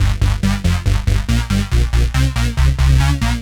FR_Roughas_140-G.wav